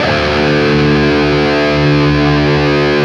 LEAD D 1 CUT.wav